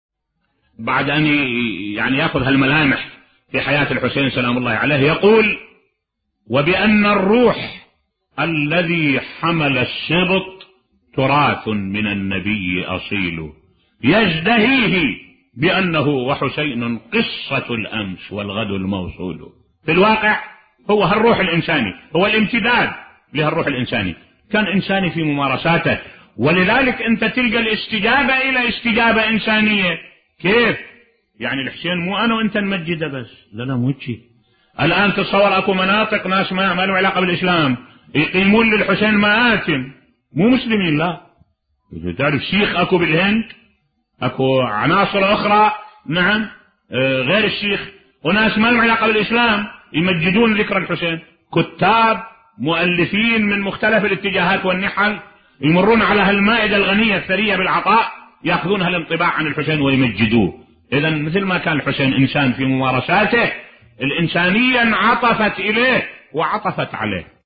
ملف صوتی حتى السيخ في الهند يمجدون الحسين بصوت الشيخ الدكتور أحمد الوائلي